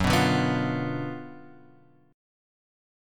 F#M7sus4#5 chord {2 2 0 x 0 1} chord